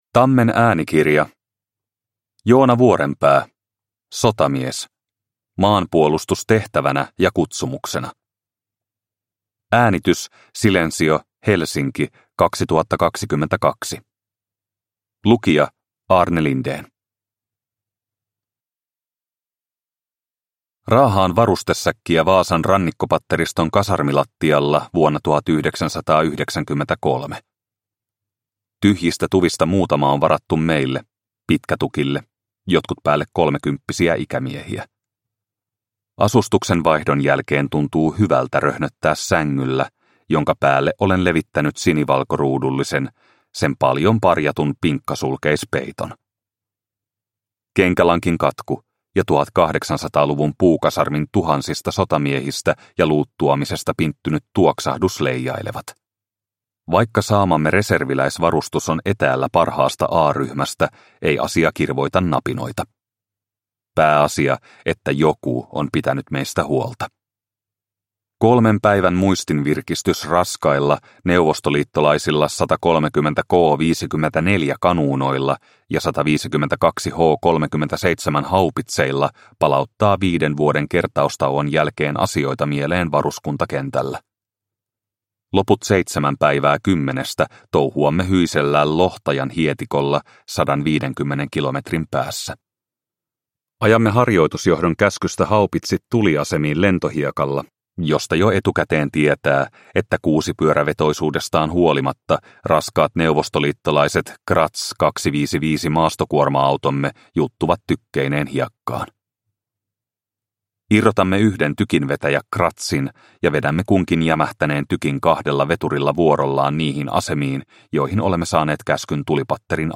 Sotamies – Ljudbok – Laddas ner